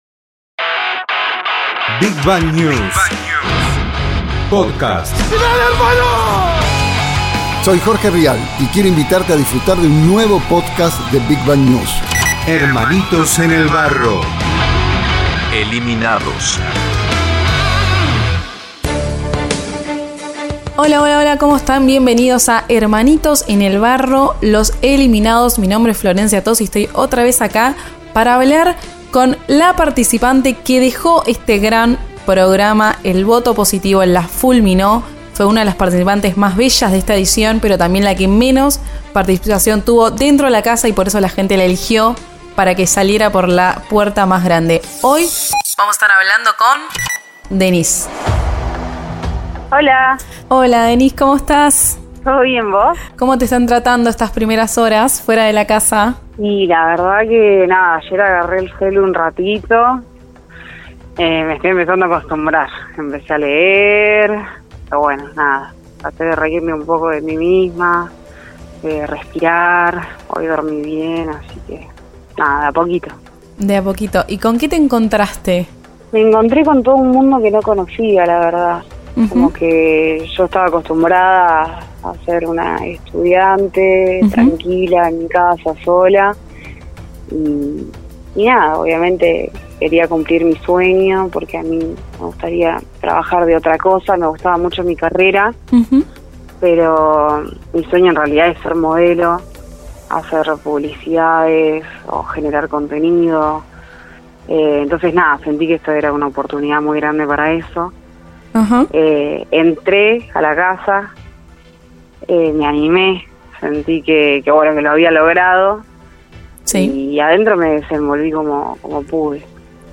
💣 Un mano a mano imperdible